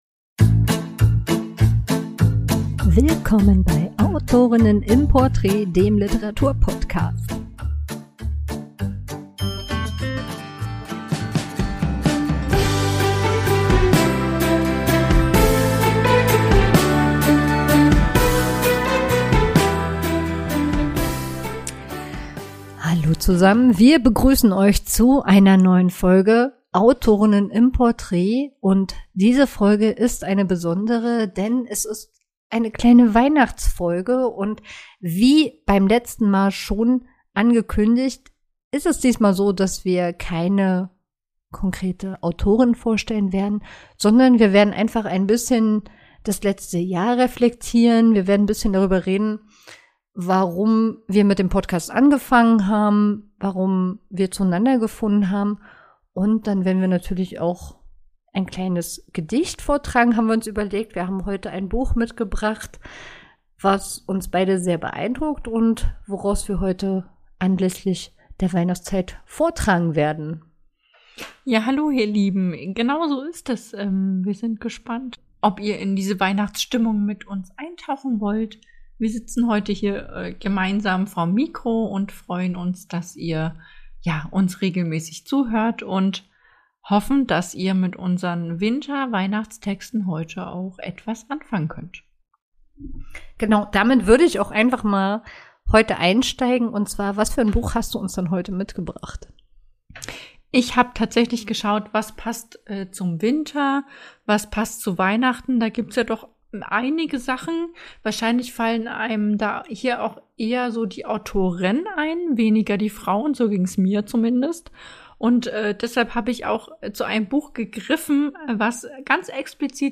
Wir wünschen euch in dieser Folge mit samt den von uns vorgetragenen Gedichten frohe Weihnachten, einen guten Rutsch und freuen uns, wenn ihr auch im kommenden Jahr wieder reinlauscht.